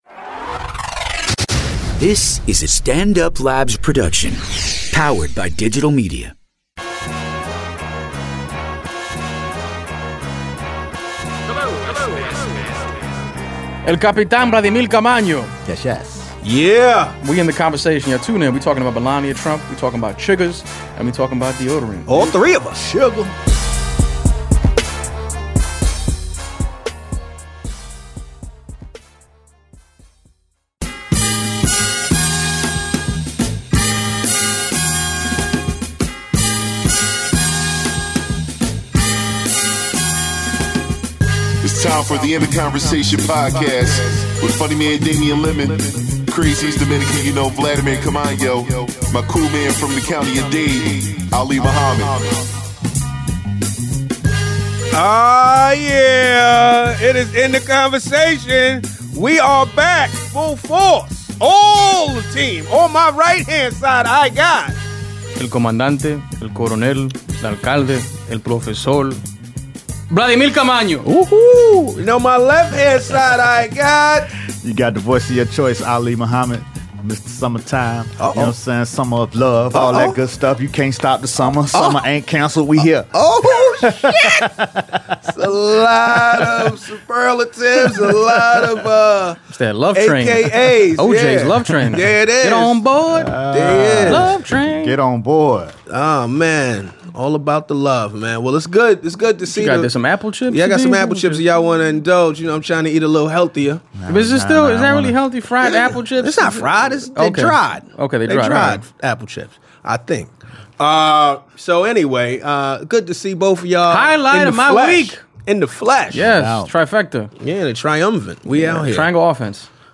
The full team is back together in studio